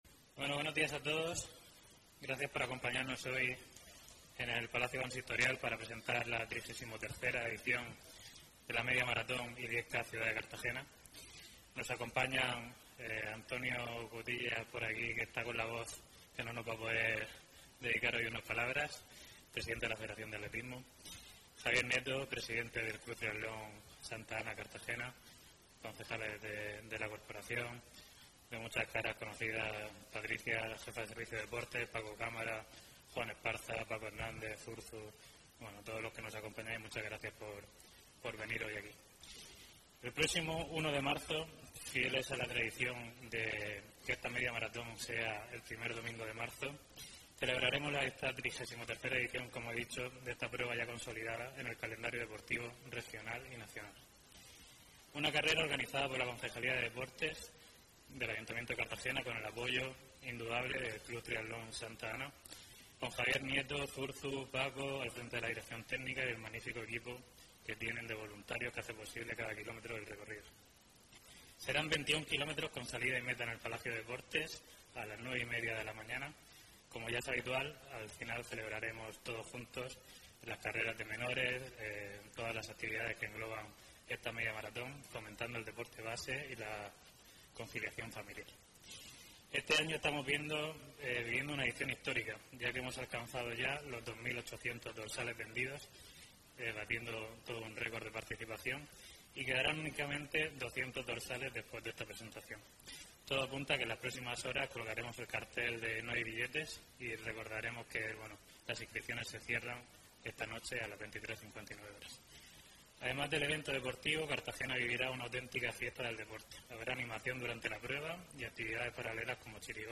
Presentación de la Media Maratón de Cartagena 2026